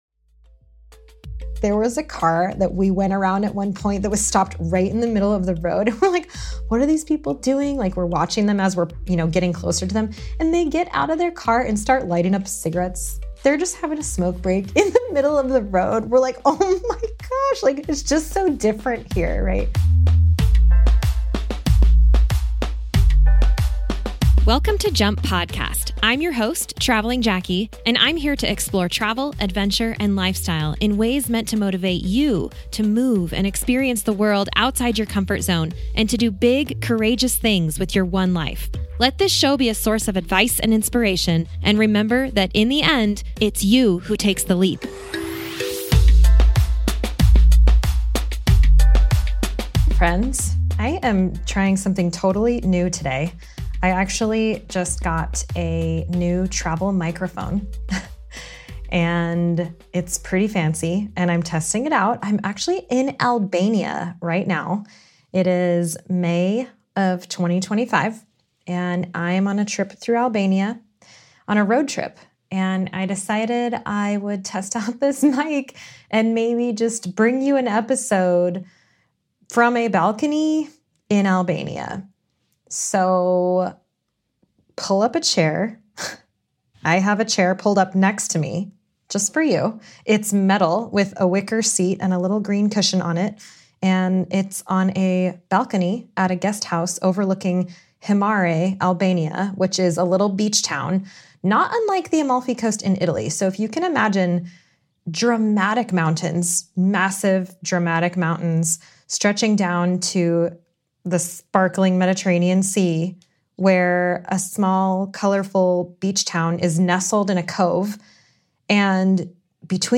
solo episode on a balcony overlooking the sea in Albania